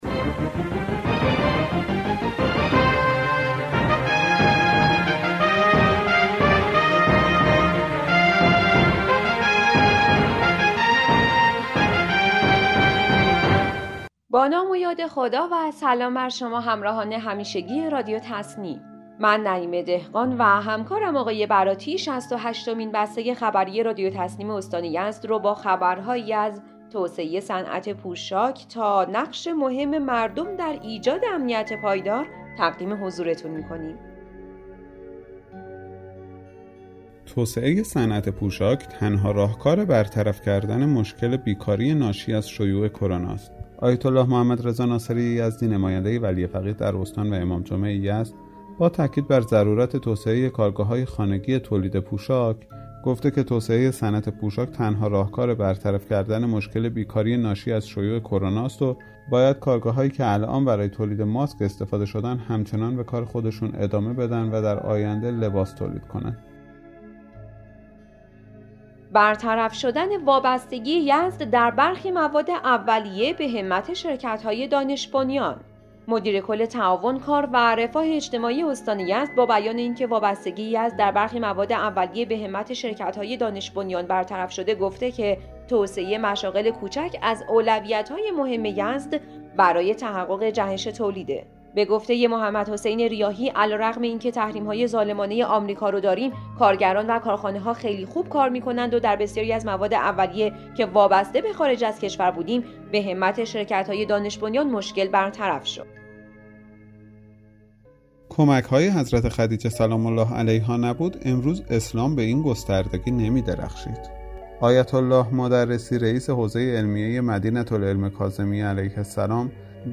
به گزارش خبرگزاری تسنیم از یزد, شصت و هشتمین بسته خبری رادیو تسنیم استان یزد با خبرهایی از توسعه صنعت پوشاک راه حل مشکل بیکاری, برطرف شدن وابستگی یزد در برخی مواد اولیه به همت شرکت‌های دانش بنیان, نقش کمک‌های حضرت خدیجه(س) در توسعه اسلام، سازش 50 درصد از دعاوی در شوراهای حل اختلاف استان در سال 98, برگزاری انتخابات مجامع هلال احمر استان یزد، توزیع 700 سبد معیشتی بین نیازمندان بخش بهمن، کشف مزرعه ارز دیجیتال و نقش مهم مردم در ایجاد امنیت پایدار منتشر شد.